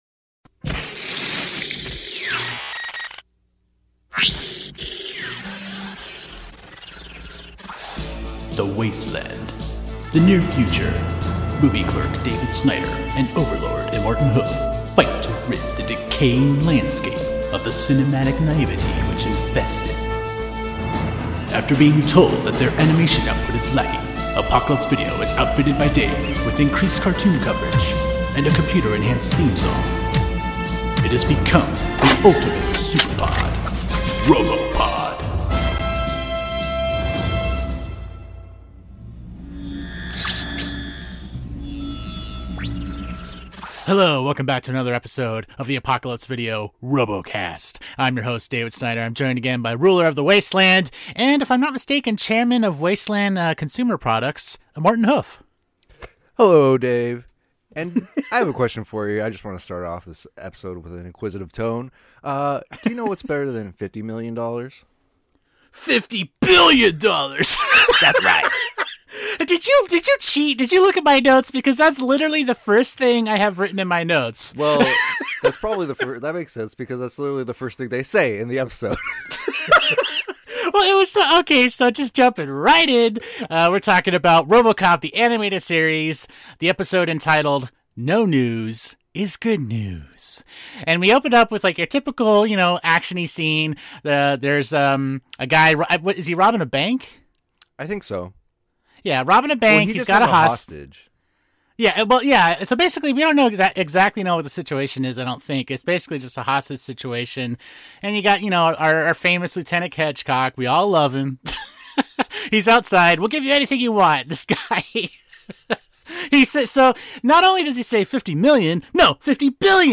SECTOR 2 - APOCALYPSE VIDEO CARTOON DISCUSSION IN PROGRESS…